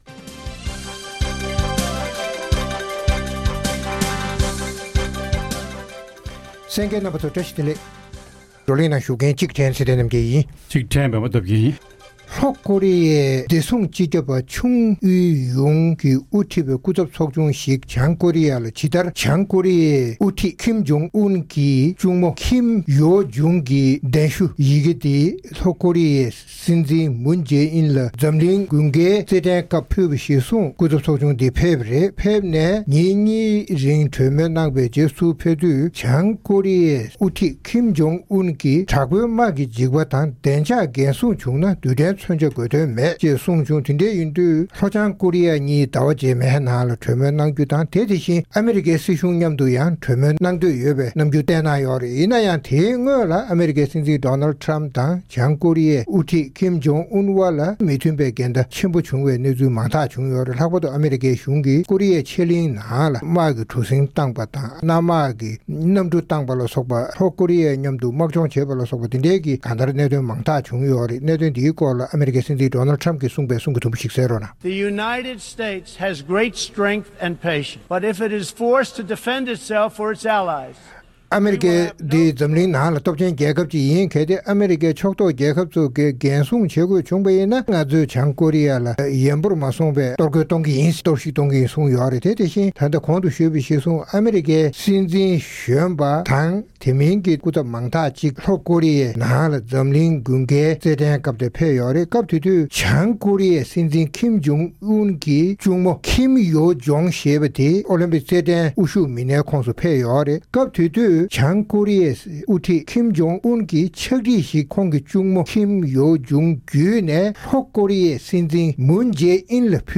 རྩོམ་སྒྲིག་པའི་གླེང་སྟེགས་ཞེས་པའི་ལེ་ཚན་ནང་། ཉེ་ལམ་ལྷོ་ཀོ་རི་ཡའི་བདེ་སྲུང་སྤྱི་ཁྱབ་པས་དབུ་ཁྲིད་པའི་སྐུ་ཚབ་ཚོགས་ཆུང་གིས་བྱང་ཀོ་རི་ཡའི་དབུ་ཁྲིད་ཀིམ་ཇོང་ཨུན་Kim Jong Un དང་གྲོས་མོལ་གནང་སྟེ་ཕྱོགས་གཉིས་དབར་ཞི་འགྲིག་འབྱུང་ཐབས་གནང་རྒྱུ་མ་ཟད། ཨ་མེ་རི་ཁ་དང་མཉམ་དུ་གྲོལ་མོལ་གནང་འདུན་ཡོད་ལུགས་གསུངས་པ་བཅས་ཀྱི་གནད་དོན་སྐོར་བགྲོ་གླེང་གནང་བ་ཞིག་གསན་རོགས་གནང་།